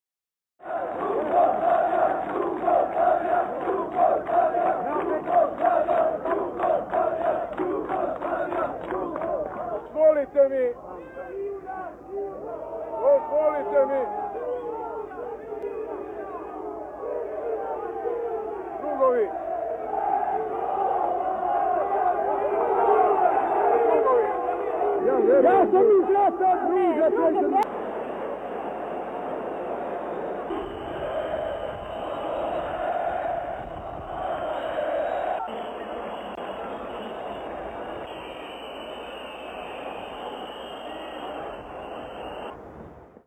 Morbid Soundfields From Iraq
Street demonstration, passionate chants, whistle people.....
Wdisc10_13streetdemonstration.mp3